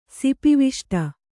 ♪ sipi viṣṭa